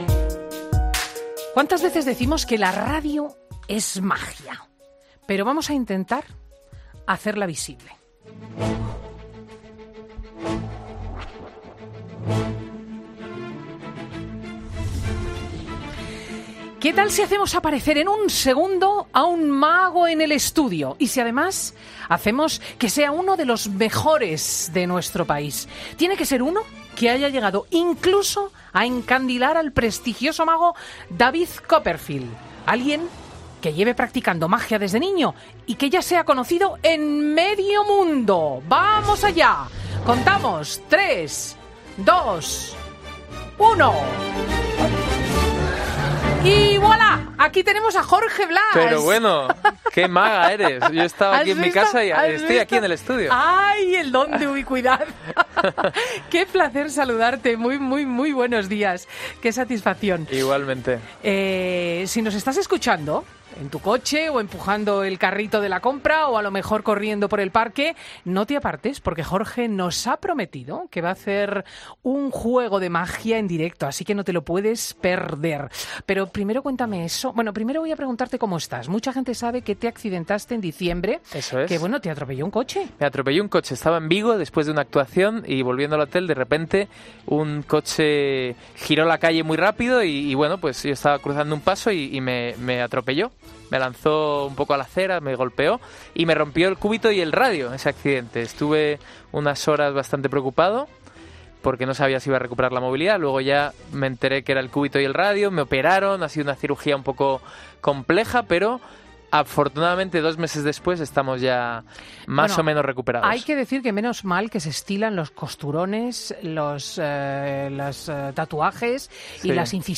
El mago Jorge Blass en 'Fin de Semana'
ESCUCHA LA ENTREVISTA COMPLETA | Jorge Blass en ‘Fin de Semana’ ¿En qué consiste el truco que le compró David Copperfield?